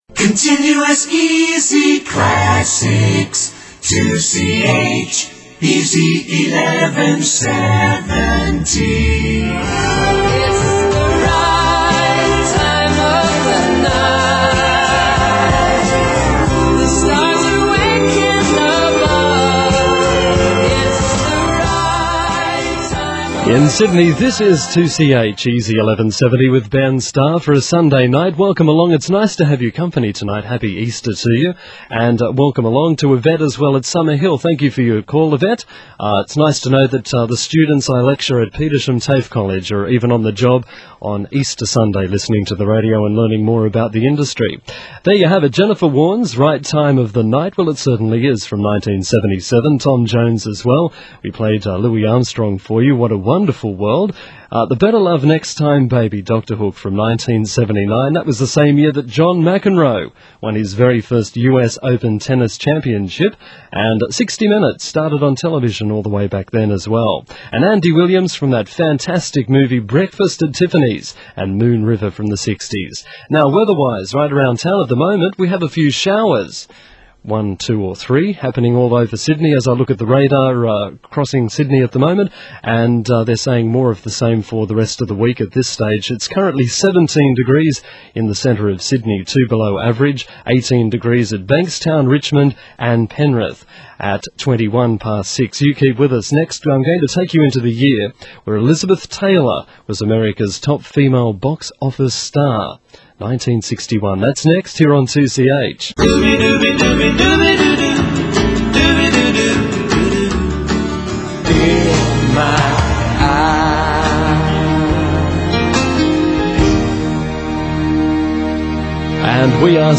promos: Other